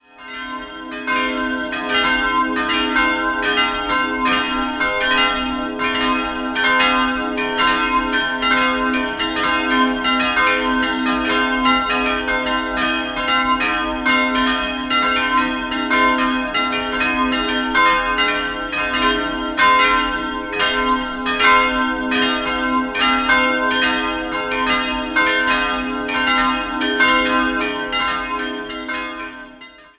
Im Jahr 2022 feiert der Meilenhofener Leonhardiritt sein 600-jähriges Bestehen. 4-stimmiges ausgefülltes C-Moll-Geläut: c''-es''-f''-g'' Die kleine Glocke wurde von Urs Laubscher 1688 in Ingolstadt gegossen, die drei anderen 1950 von Karl Czudnochowsky in Erding.